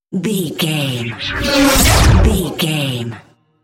Whoosh to hit engine speed
Sound Effects
futuristic
intense
the trailer effect